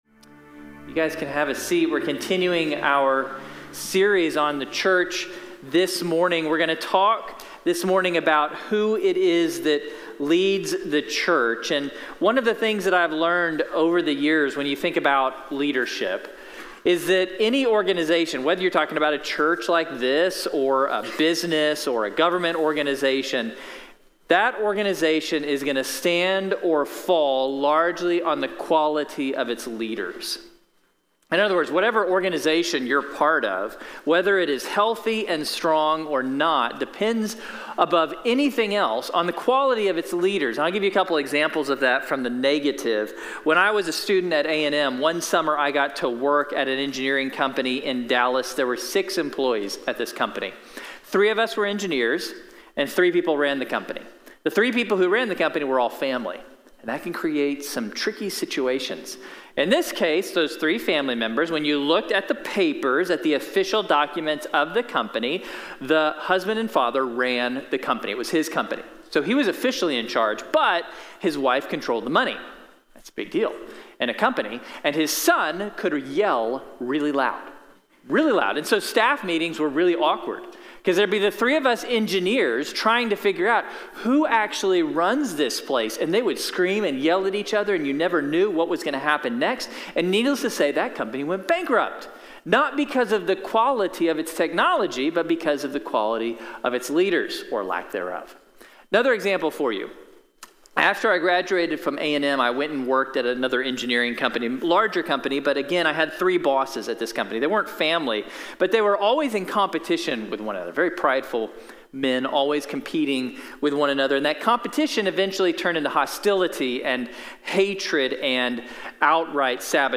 | Sermón de la Iglesia Bíblica de la Gracia